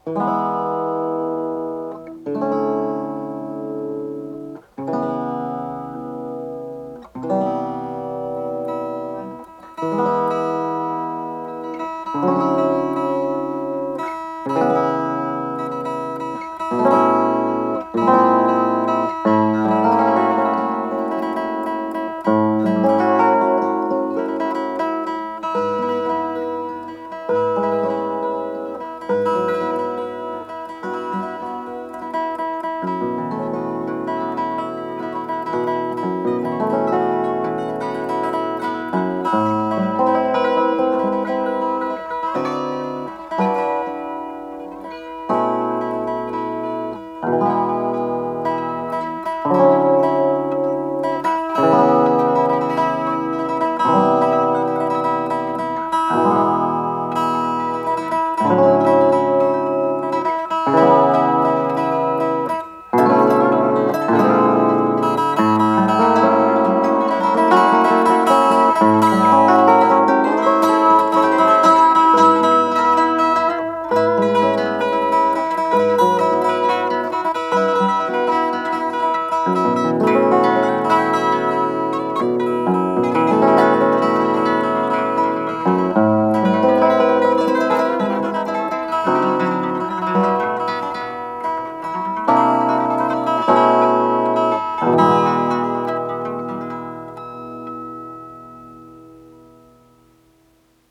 с профессиональной магнитной ленты
ПодзаголовокМузыкальная миниатюра
гитара
ВариантДубль моно